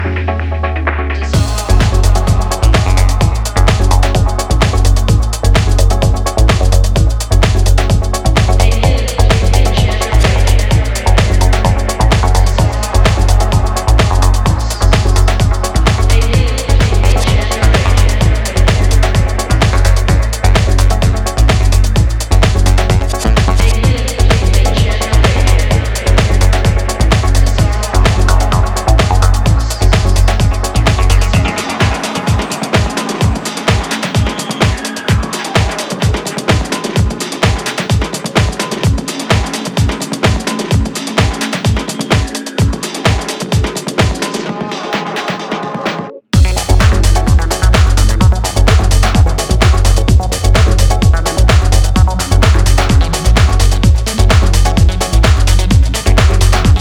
electronic duo